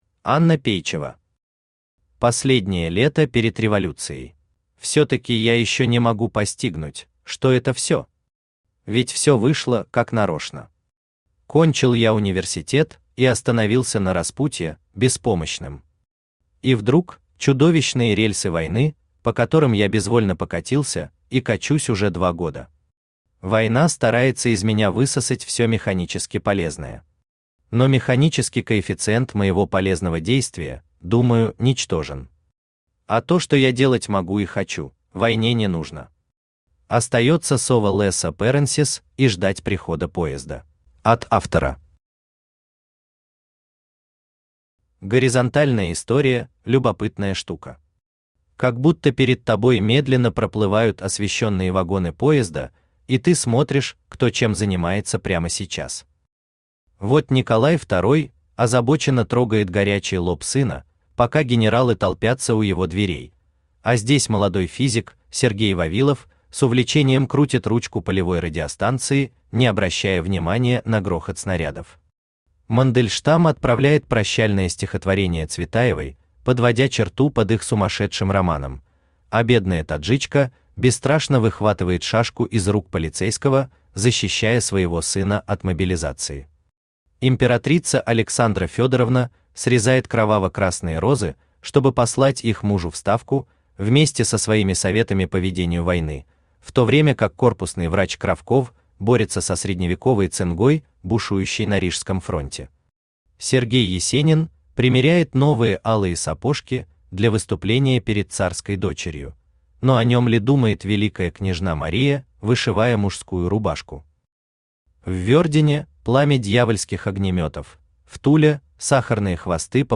Аудиокнига Последнее лето перед революцией | Библиотека аудиокниг
Aудиокнига Последнее лето перед революцией Автор Анна Пейчева Читает аудиокнигу Авточтец ЛитРес.